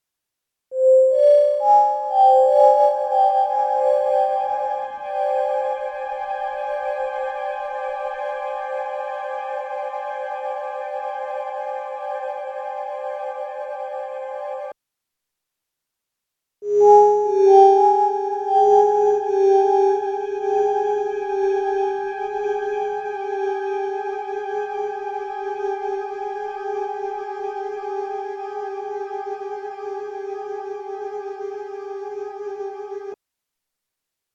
I recorded a patch from my Digitone with fx on it. I then went and sampled that in stereo on my Sampletrak, pitched it around and did this. This is a dry recording.